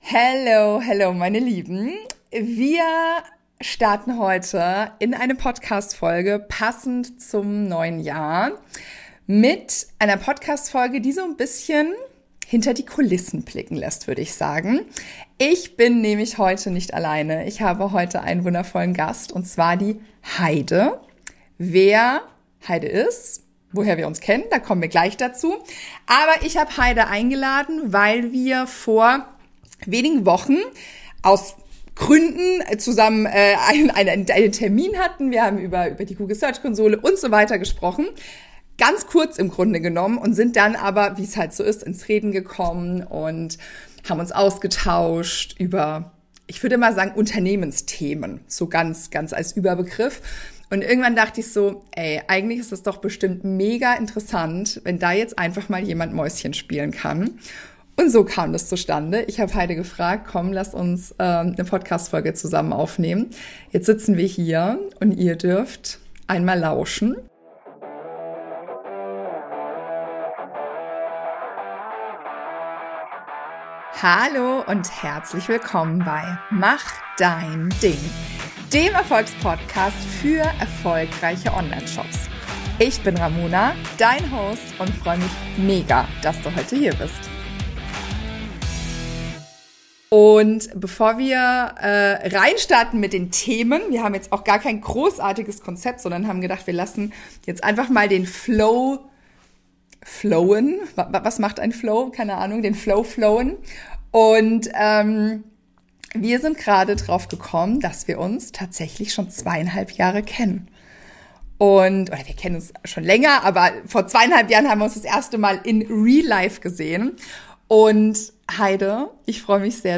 Selbstständig und einsam? Ein ehrliches Gespräch